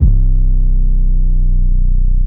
Trapperz 808s (Slide) (2).wav